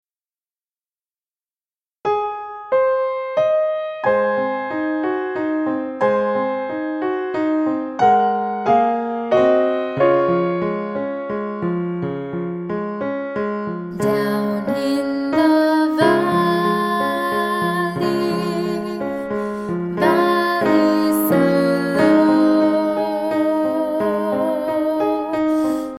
Traditional Children's Song Lyrics and Sound Clip